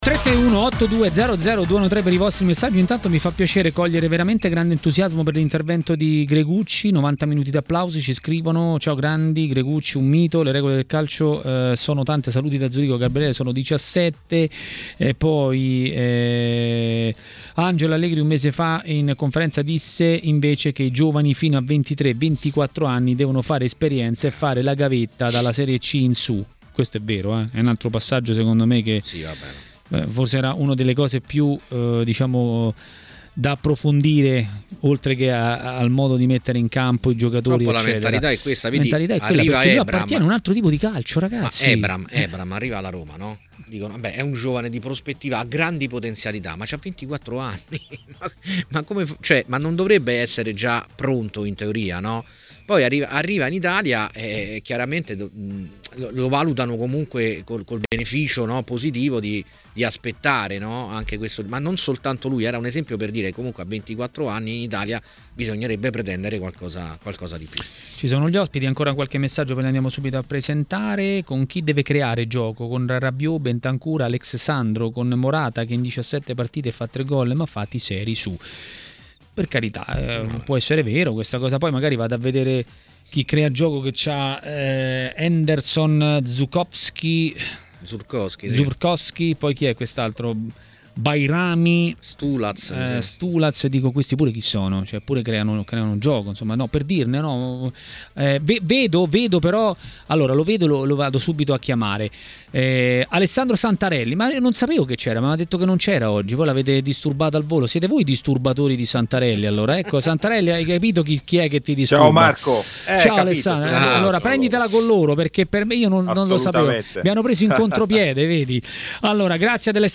L'ex tecnico Gigi Maifredi a TMW Radio, durante Maracanà, ha detto la sua sul momento della Juventus.